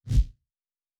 Lightsaber 2_3.wav